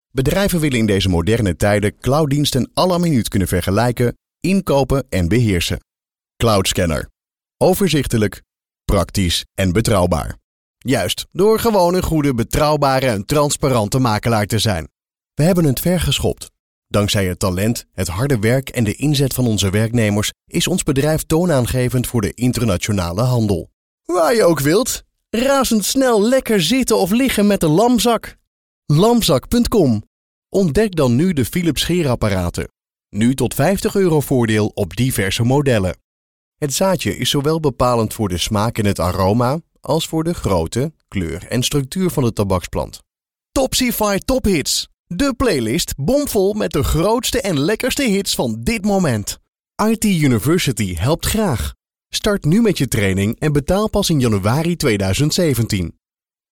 NL MK EL 01 eLearning/Training Male Dutch